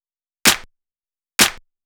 VTDS2 Song Kits 128 BPM Pitched Your Life